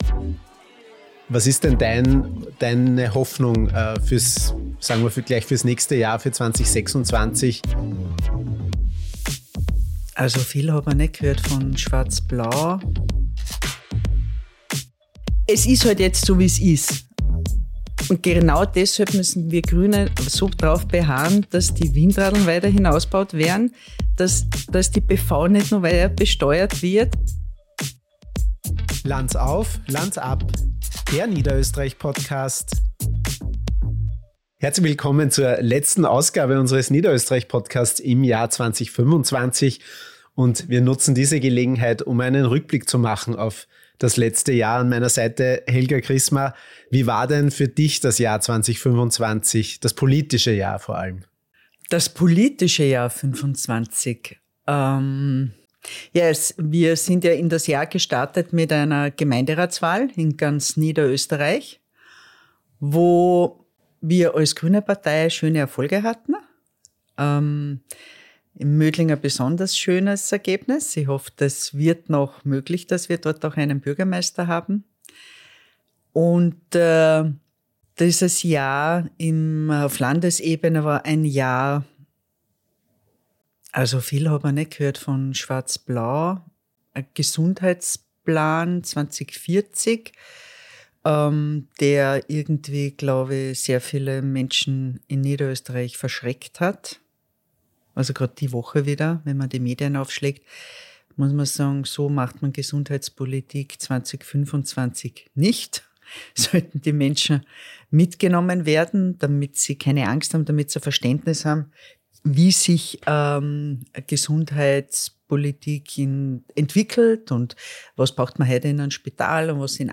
- Green Deal vs. konservative Orientierungskrise - Transformation statt Stillstand: Erneuerbarer Aufbruch Ein ehrliches Gespräch über die Realität und die Chancen für 2026.